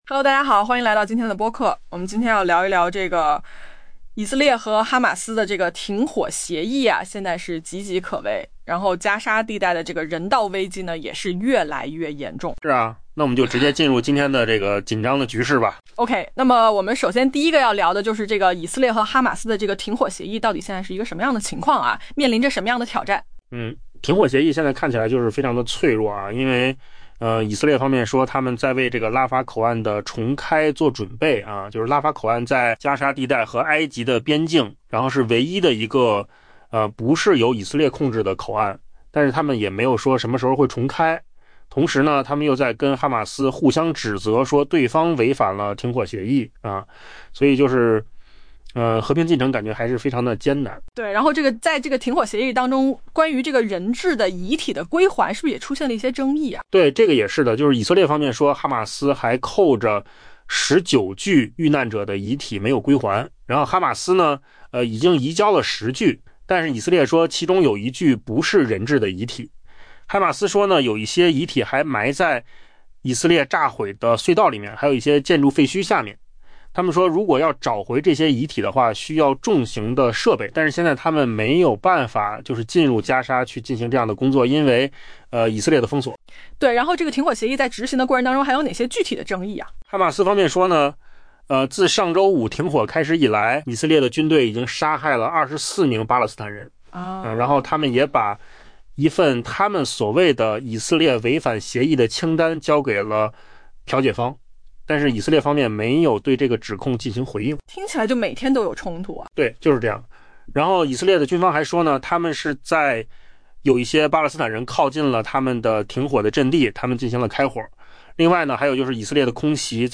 AI 播客：换个方式听新闻 下载 mp3 音频由扣子空间生成 以色列周四表示，正为加沙地带与埃及之间的拉法口岸重开做准备，以允许巴勒斯坦人出入，但未设定具体日期——与此同时， 以方与哈马斯就 「违反美国斡旋停火协议」 互相指责。